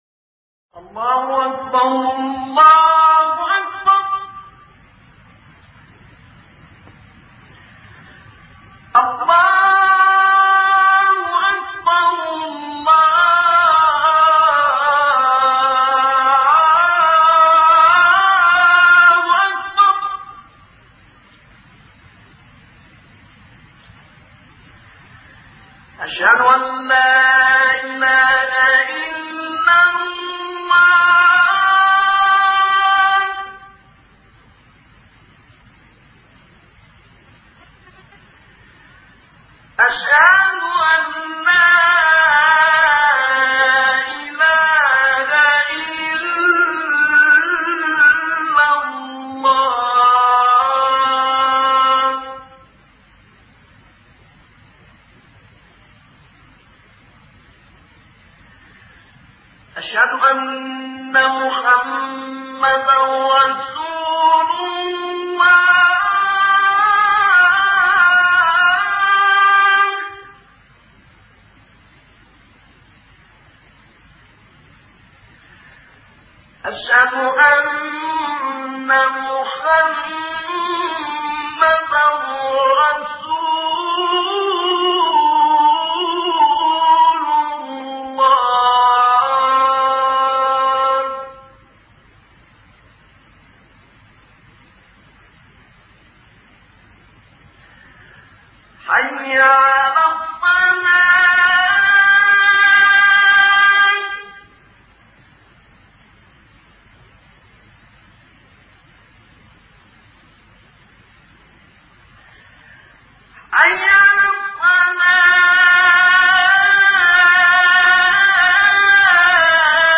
الأذان القاهرة - أبو العنين شعيشع - قسم أغســــل قلــــــبك1
أناشيد ونغمات